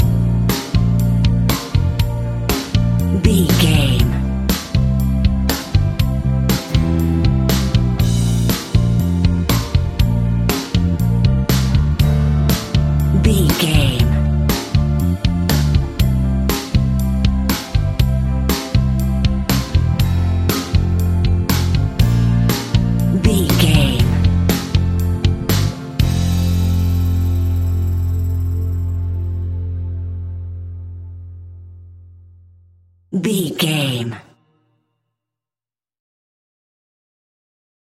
Ionian/Major
pop rock
indie pop
fun
energetic
uplifting
instrumentals
upbeat
groovy
guitars
bass
drums
organ